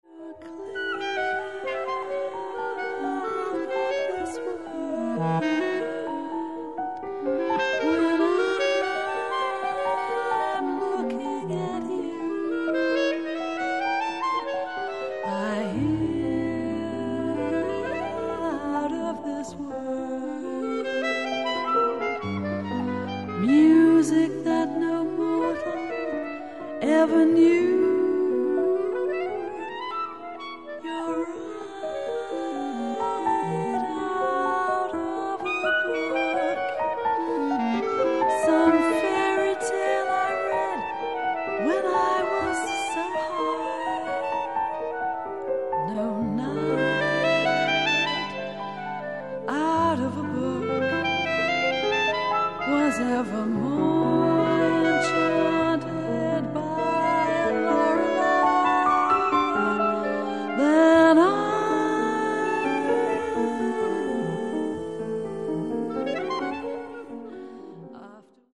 Recorded July 1986 at Rainbow Studio, Oslo.
Voice
Piano
Clarinet, Tenor Saxophone